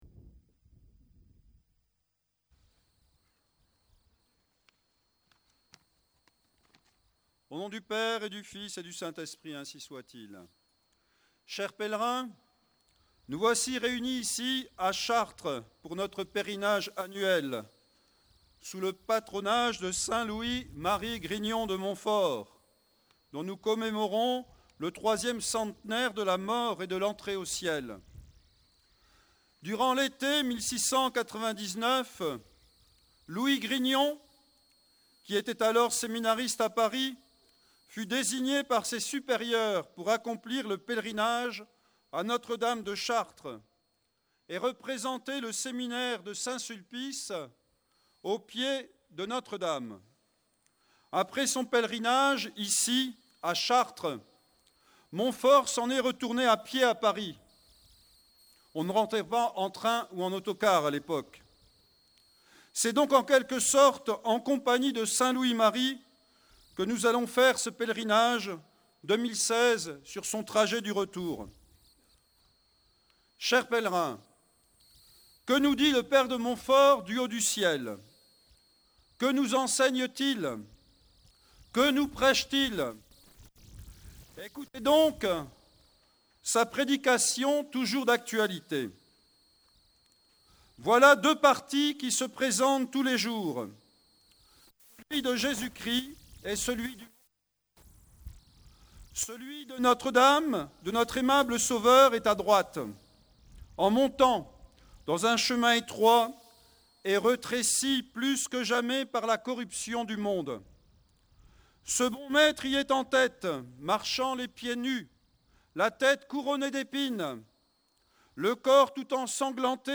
Les sermons du Pèlerinage de Pentecôte 2016 - Pèlerinages de Tradition